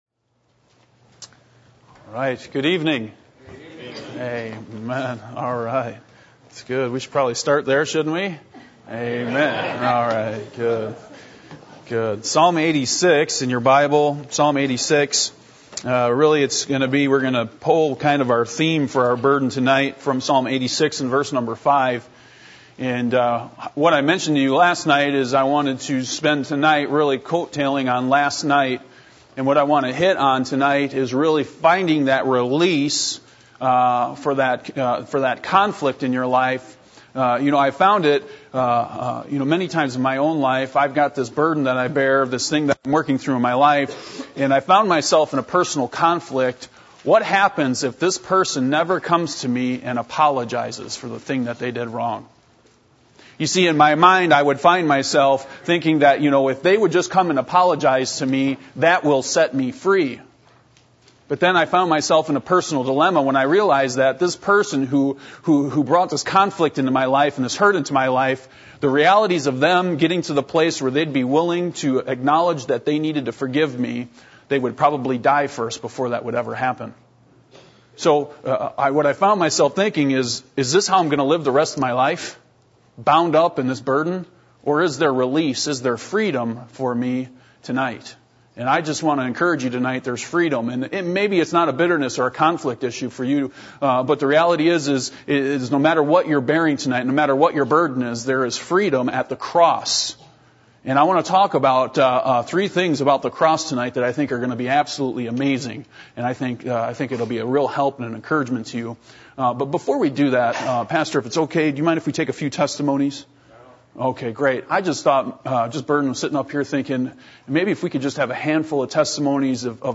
Passage: Psalm 86:1-17 Service Type: Revival Meetings %todo_render% « Lord